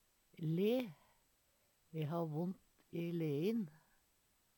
le - Numedalsmål (en-US)